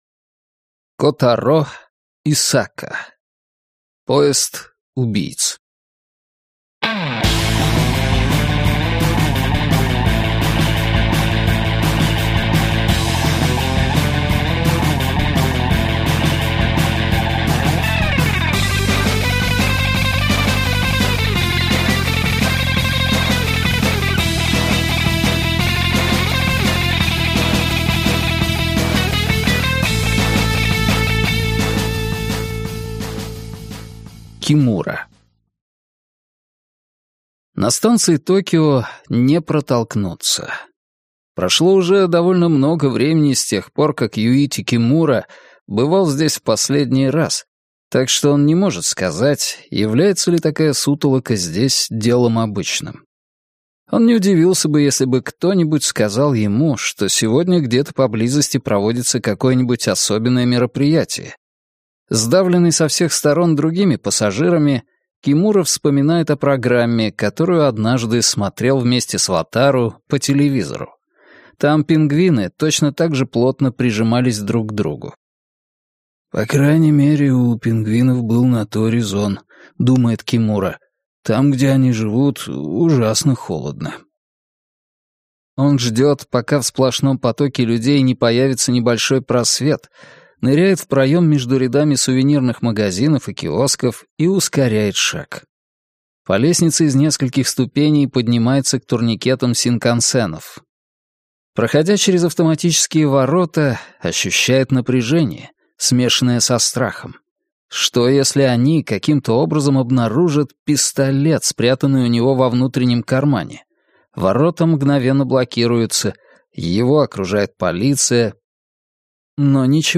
Аудиокнига Поезд убийц | Библиотека аудиокниг